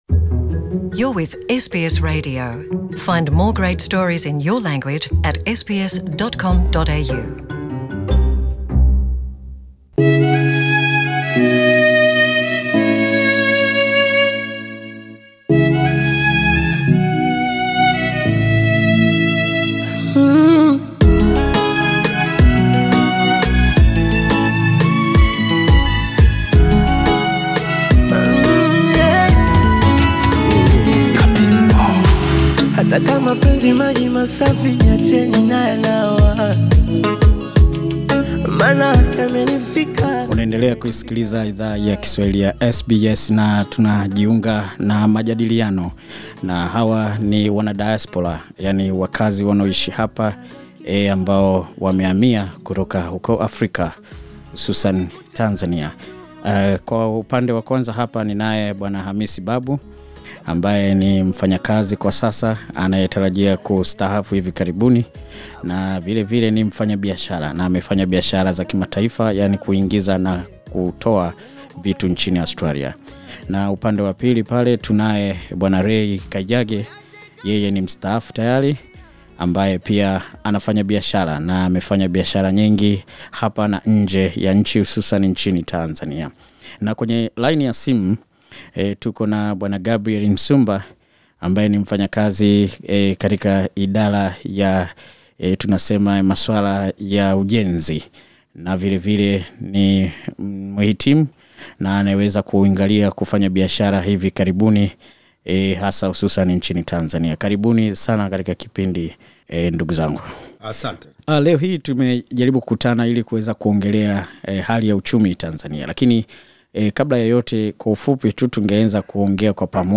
Kumekuwa na maswali mengi juu ya ukuaji wa uchumi wa Tanzania. SBS Swahili ilipata wasaa wa kuzungumza na baadhi ya wafanyabiashara wa kimataifa kuhusiana na mwenendo huo mzima wa uchumi.